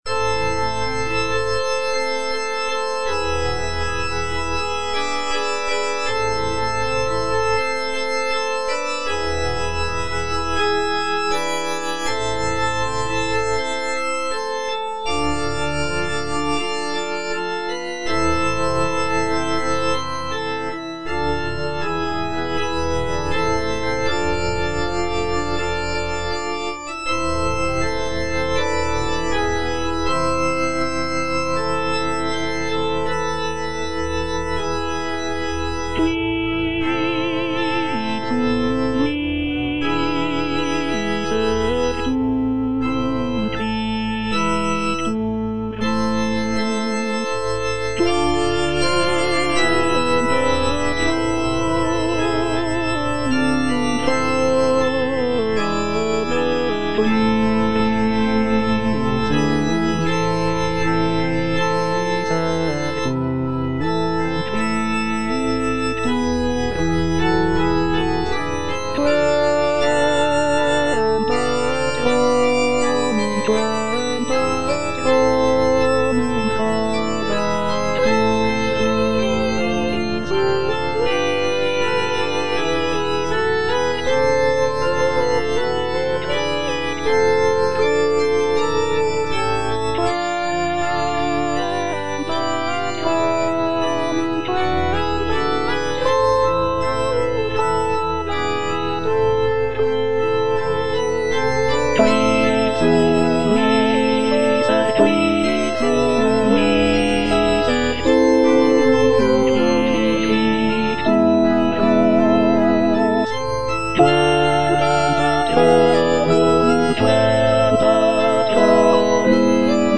All voices
is a sacred choral work rooted in his Christian faith.